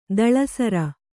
♪ daḷasara